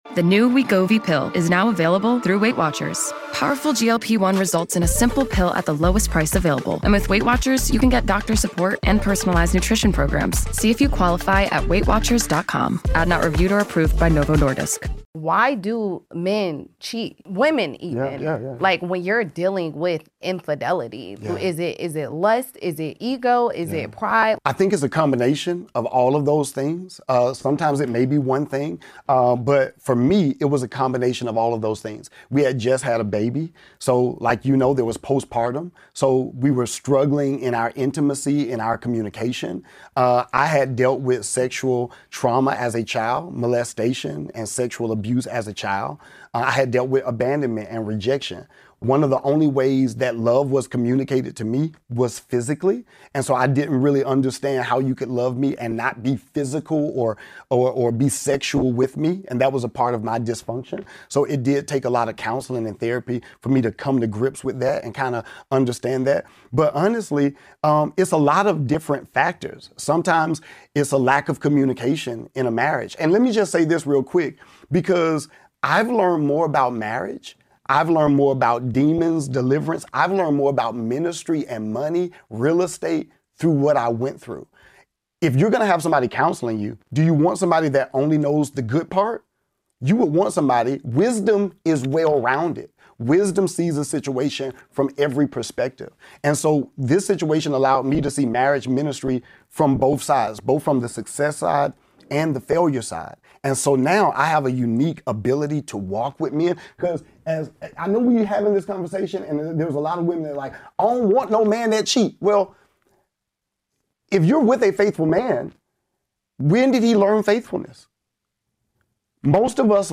A raw, honest conversation about why cheating happens, how trauma and unmet needs can distort love, what postpartum pressure can expose in a relationship, and the difference between a one time failure and chronic betrayal. Plus: what real repentance, counseling, and reconciliation actually require when a family is on the line.